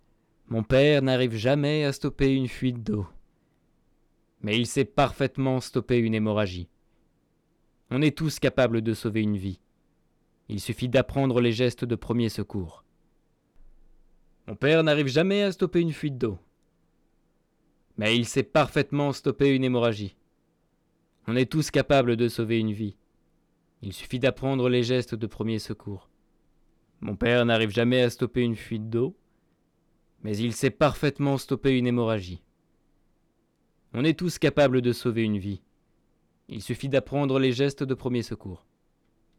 3 Extrait voix
Comédien